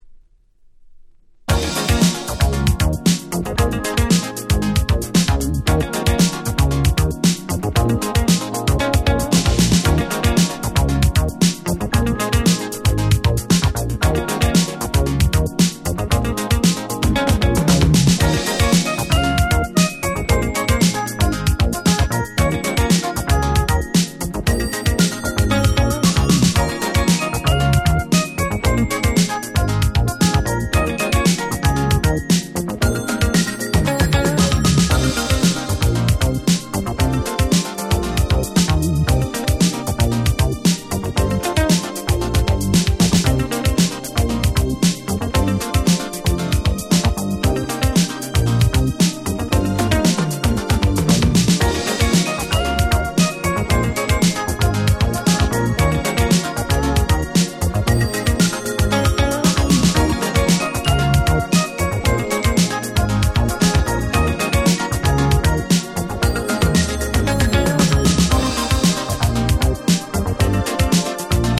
85' Electro Old School 大名曲。